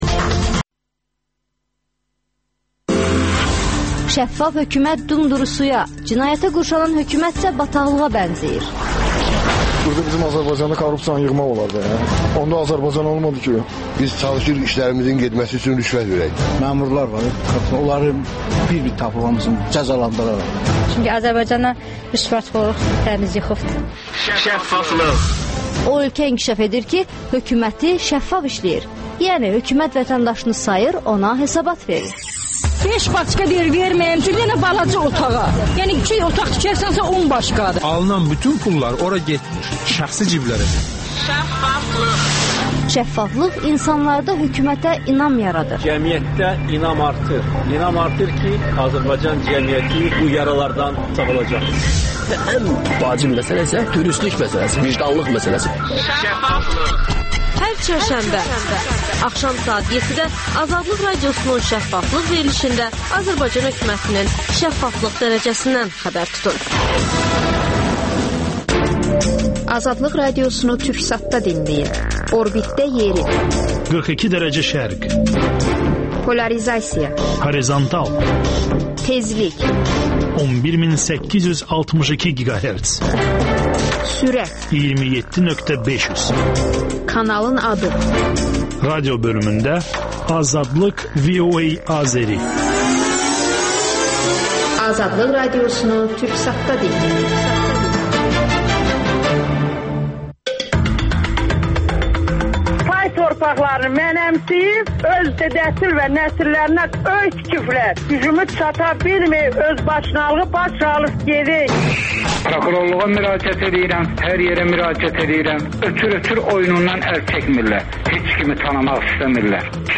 - Azərbaycan-Türkiyə-Gürcüstan sammitinin gerçək səbəbi nə ola bilər? AzadlıqRadiosunun müxbirləri ölkə və dünyadakı bu və başqa olaylardan canlı efirdə söz açırlar.